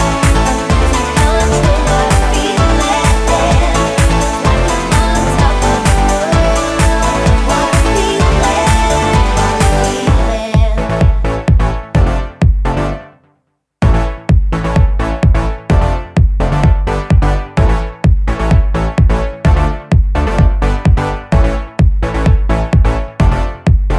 no Backing Vocals Dance 2:56 Buy £1.50